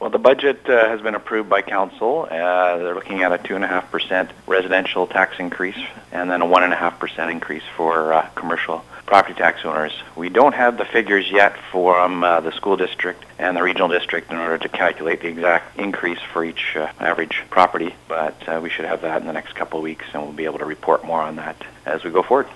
We caught up with Mayor Paul Ives to find out what residents can expect.
Ives gets into some of the projects the town has in the works.